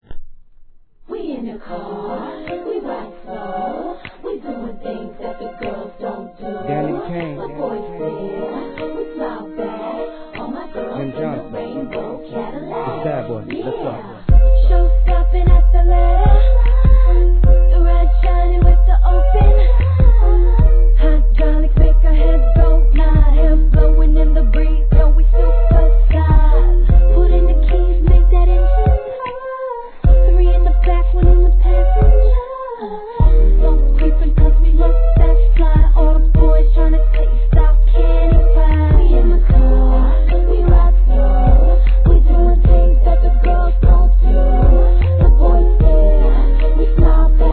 HIP HOP/R&B
タイトルフレーズのヴォーカルに合わせた「エーイ！エーイ！オー！オー！オォーー！！！」の元気の良い掛け声が何とも印象的！！